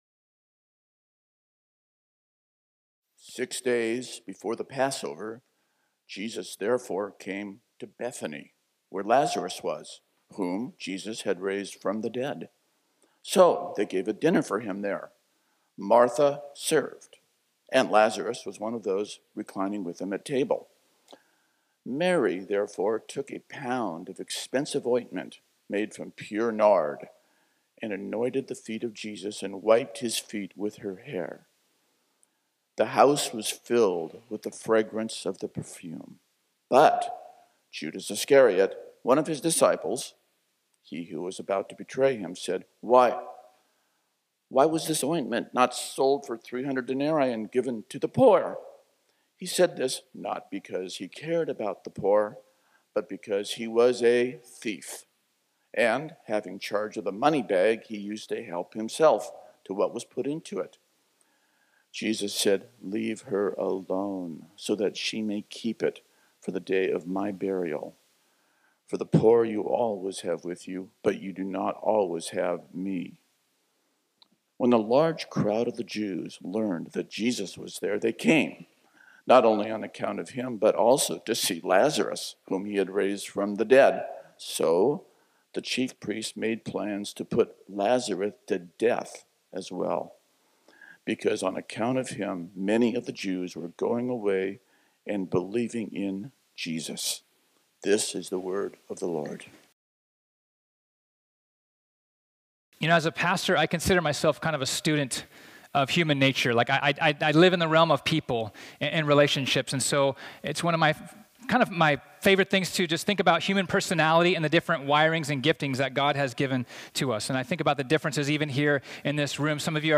sermon
This sermon was originally preached on Sunday, March 1, 2020.